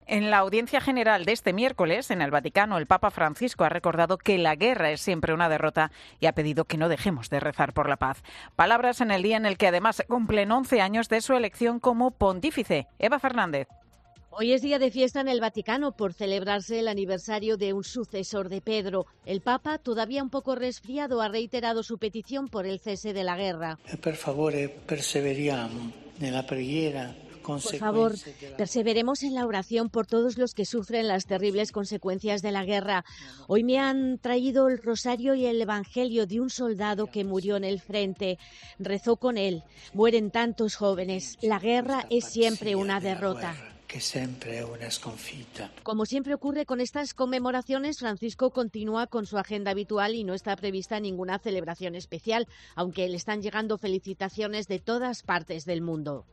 Como cada miércoles, el Papa ha celebrado la Audiencia en la Plaza de San Pedro y ha reiterado su petición por la paz.
El Pontífice sigue resfriado y no ha podido leer él la catequesis que se ha centrado en la virtud.